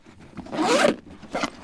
backpack_open.ogg